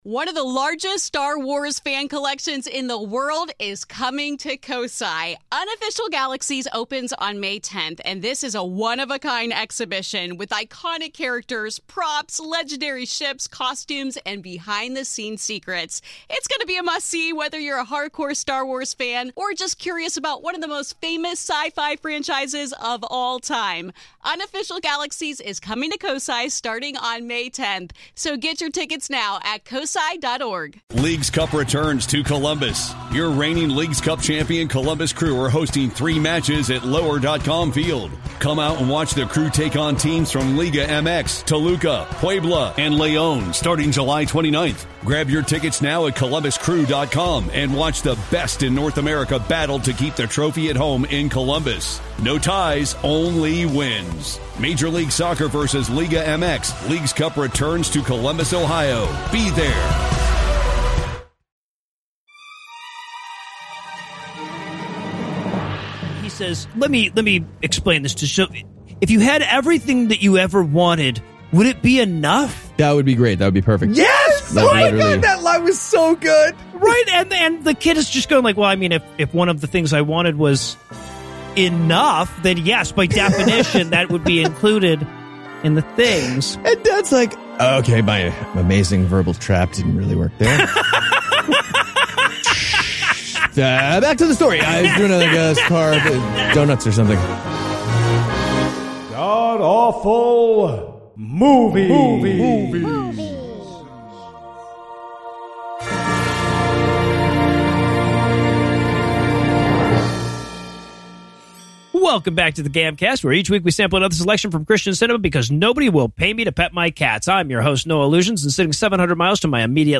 This week, we team up for an atheist review of Buying Time, the story of cars revving their engines, mostly. Also some heavy handed Jesus stuff and the occasional random GOP talking point.